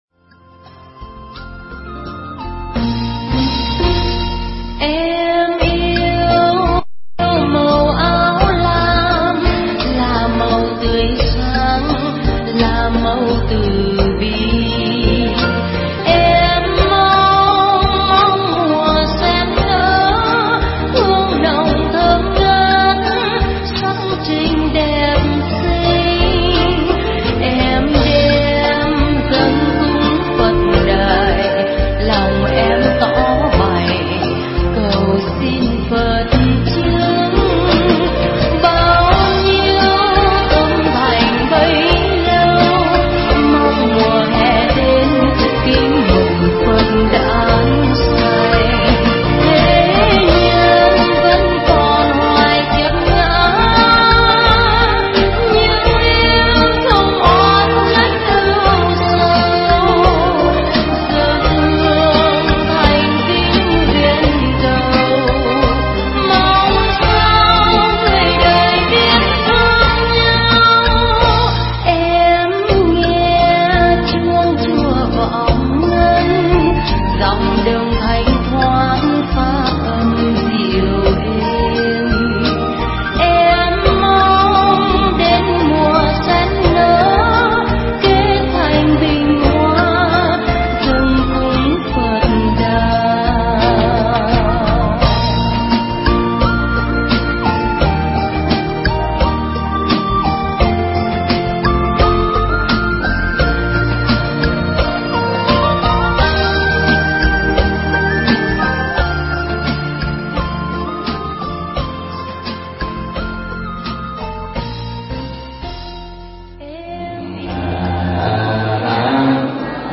Pháp âm Trở Lại Đài Sen